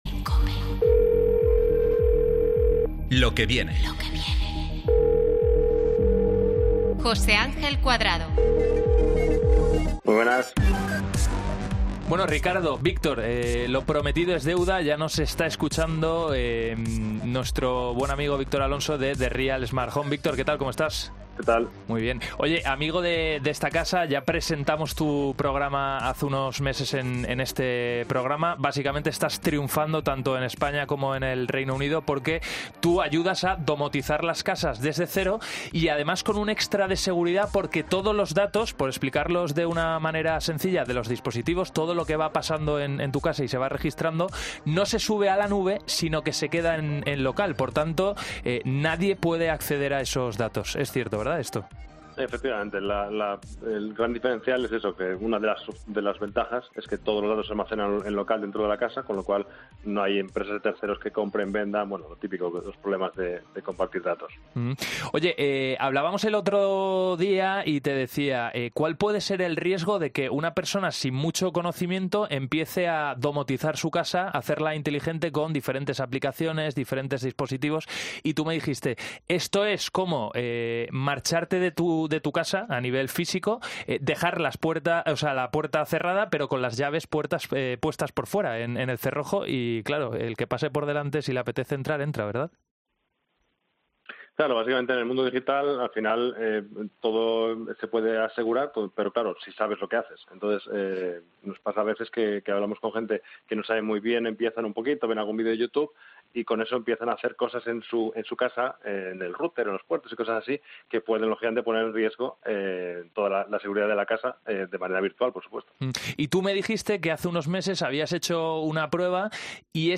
Un experto en domótica sobre cyberseguridad